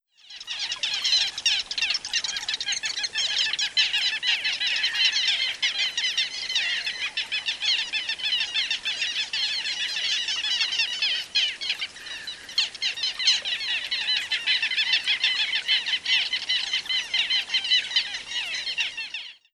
Birds and River
Bird3.wav